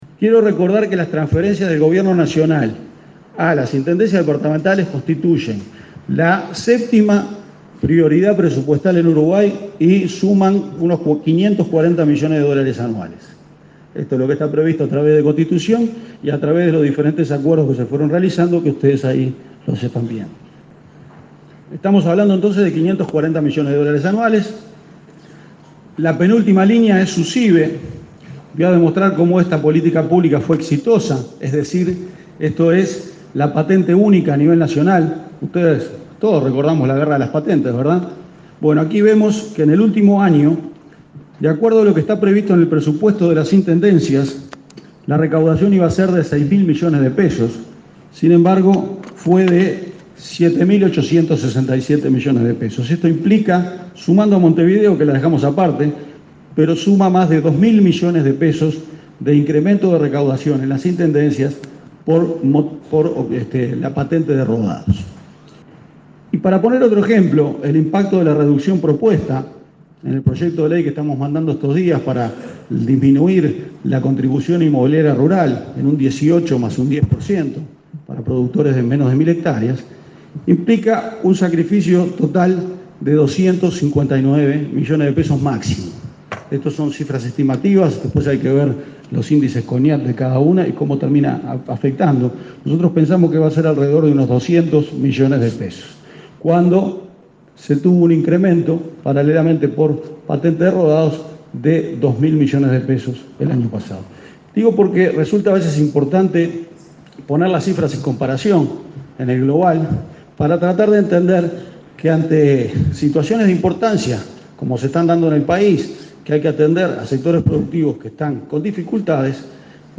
Las transferencias del Gobierno a las intendencias constituyen la 7.ª prioridad presupuestal en Uruguay y alcanzan los $ 15.500 millones anuales, ratificó el director de OPP, Álvaro García, en un foro de ACDE. Habló del aumento en la recaudación por patente de rodados, cercano a $ 2.000 millones en el último año. Sobre la rebaja de la contribución inmobiliaria rural, dijo que implicará un sacrificio fiscal de $ 259 millones.